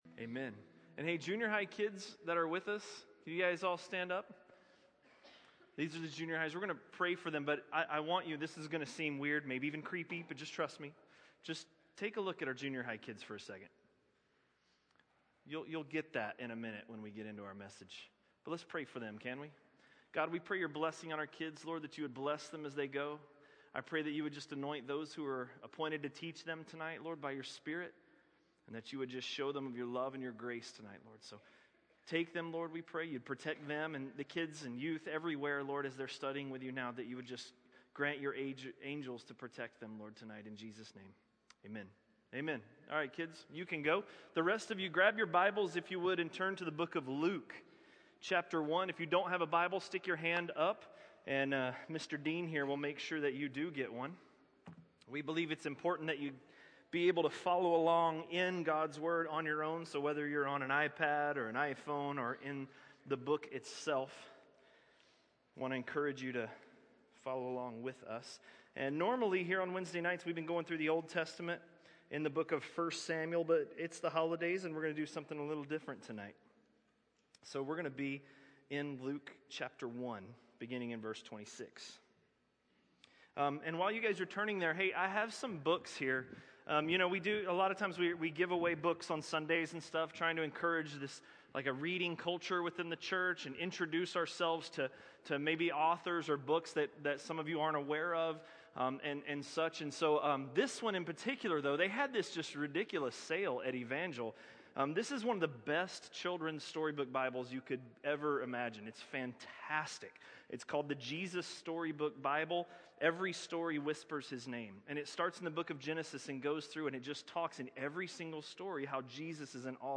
A message from the series "Luke." Luke 1:26–1:38